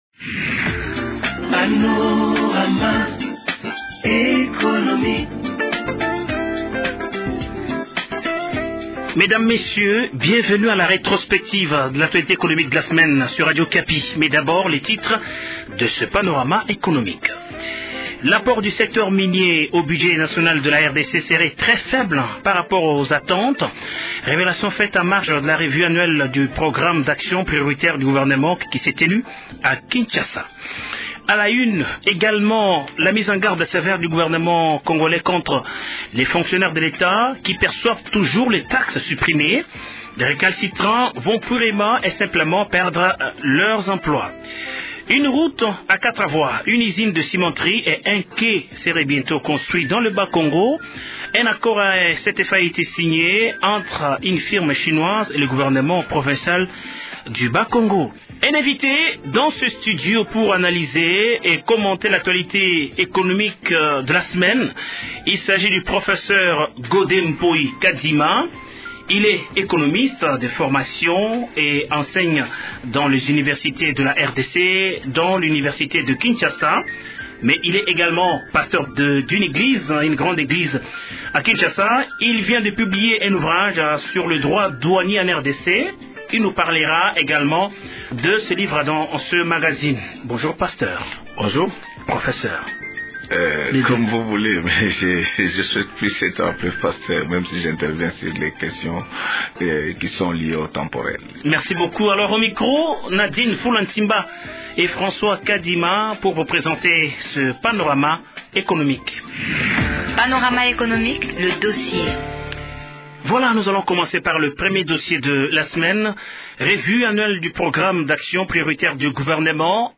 L’apport du secteur minier au budget national de la RDC serait très faible par rapport aux attentes, selon les révélations faites en marge de la revue annuelle du Programme d’action prioritaire du gouvernement qui se tient depuis vendredi 11 juillet à Kinshasa. C’est le sujet à la une du magazine de ce samedi 12 juillet.